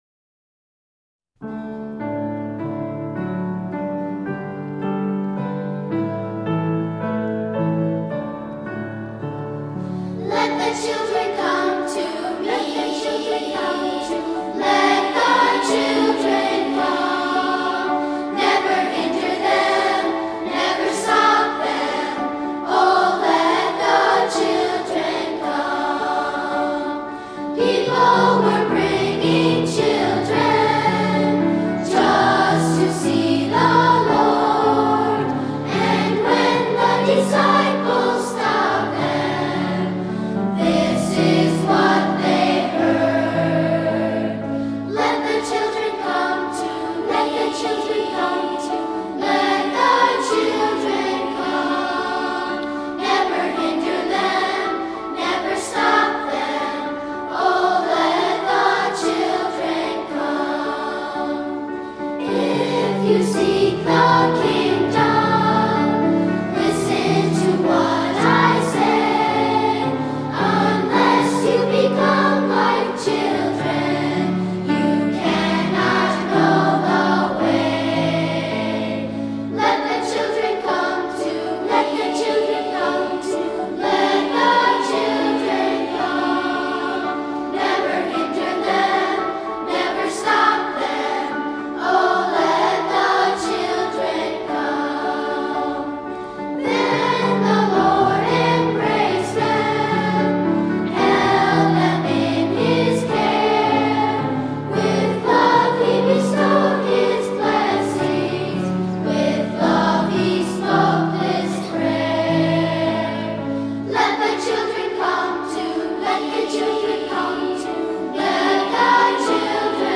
Voicing: Unison or Two-Part Choir